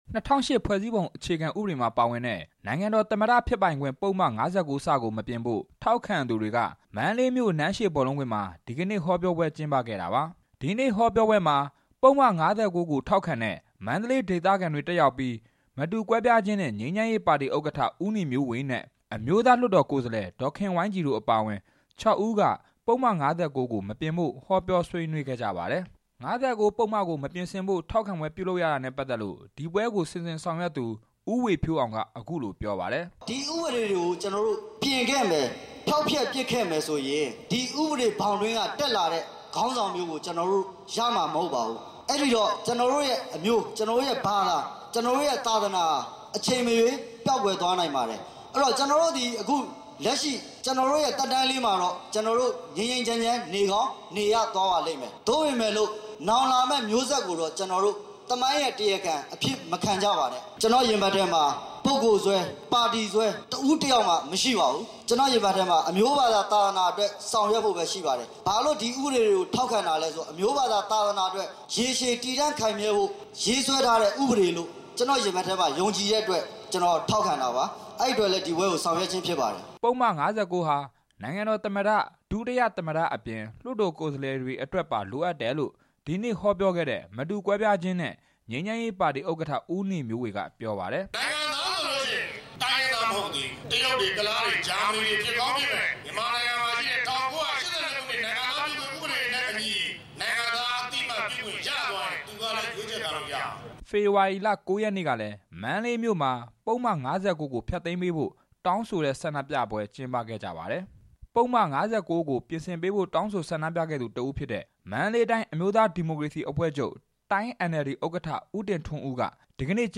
ဒီဆန္ဒပြပွဲအကြောင်း တင်ပြချက်